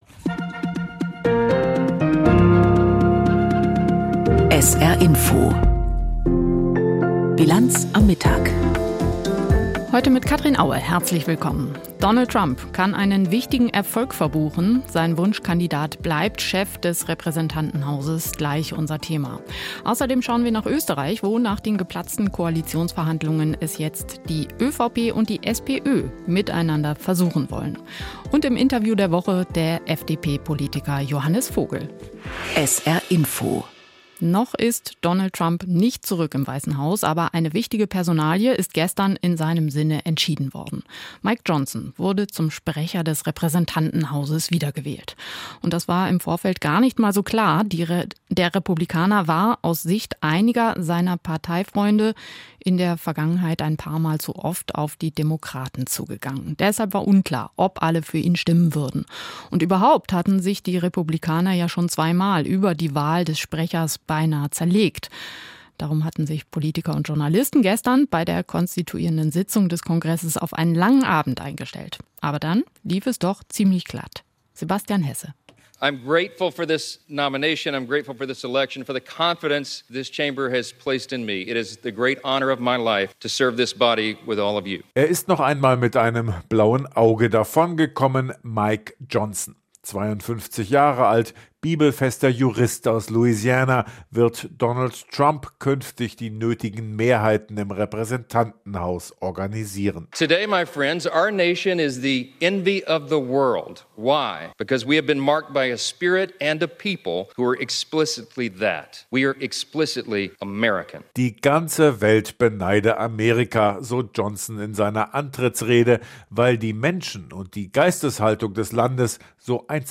Aktuelles und Hintergrnde zu Entwicklungen und Themen des Tages aus Politik, Wirtschaft, Kultur und Gesellschaft in Berichten und Kommentaren.